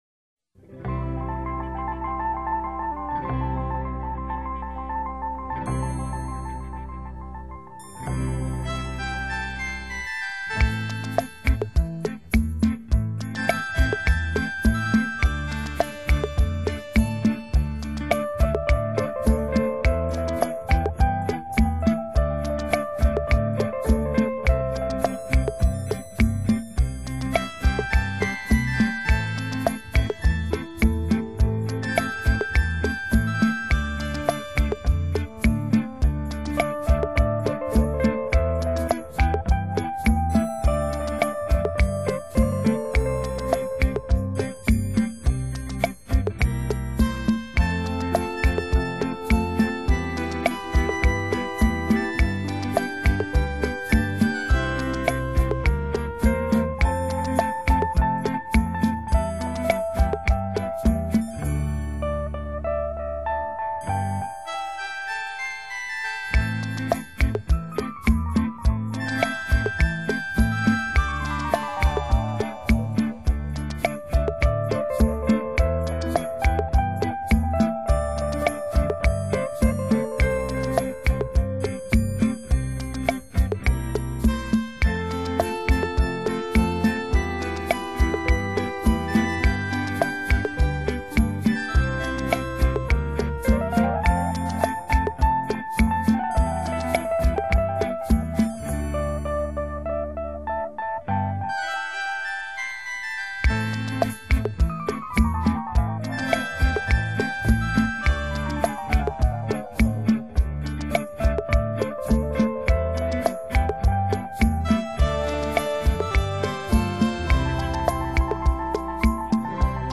CD2 Rumba 伦巴